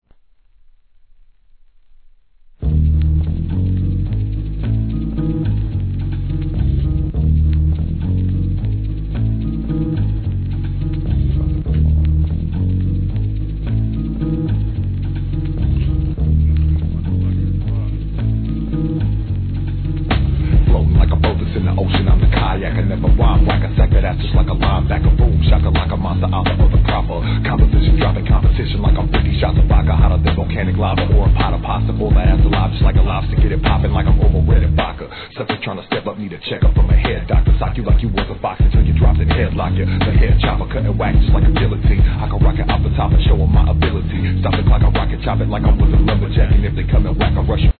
1. G-RAP/WEST COAST/SOUTH
イントロから激シブなベースラインが印象的なトラックで、COOLなマイク・リレーが見事なオフェンス!!